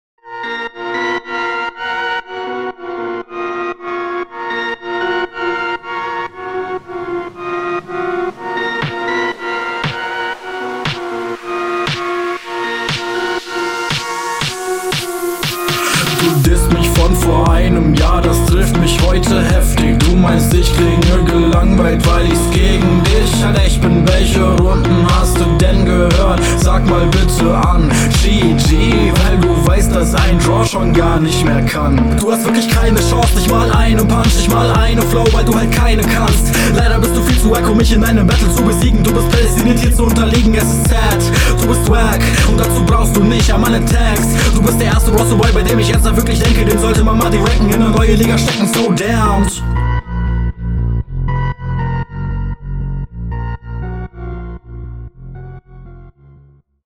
Gehört nicht in Bronze tbh, nicer flow